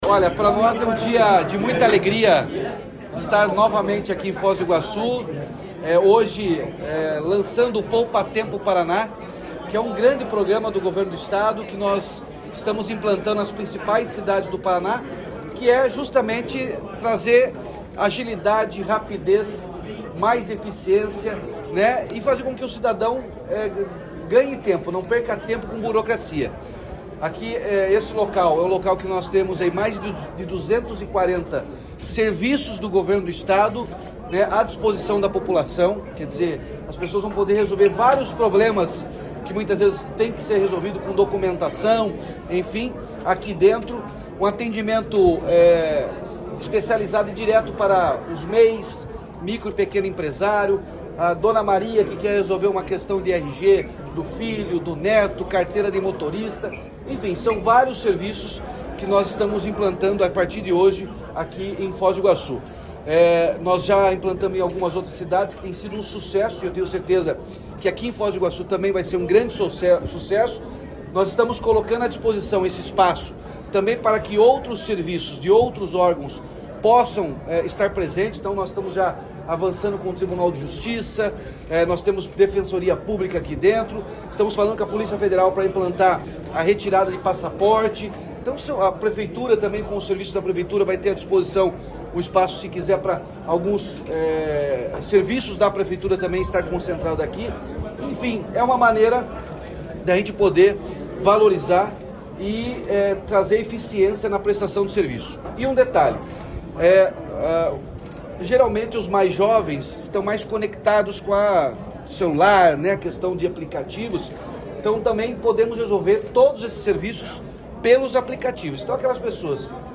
Sonora do governador Ratinho Junior sobre a nova unidade do Poupatempo Paraná de Foz do Iguaçu